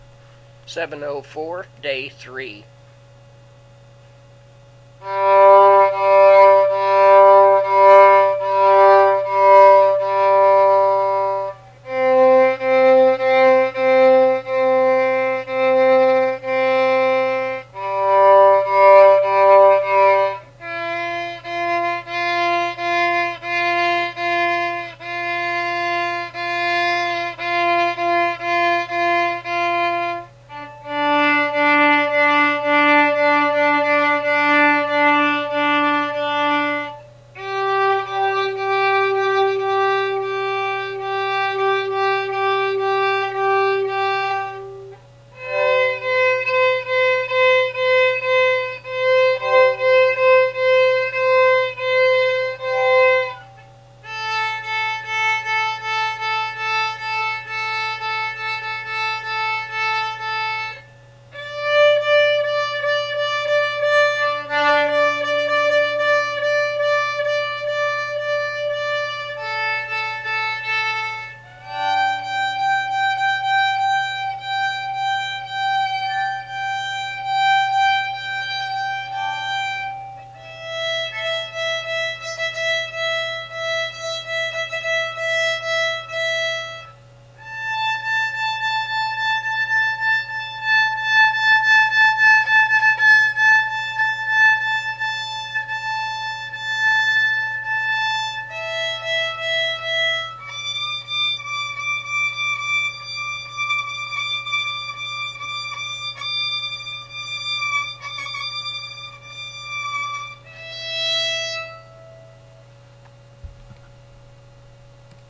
Here are audio files for 6 sets of strings so you can hear what they sound like.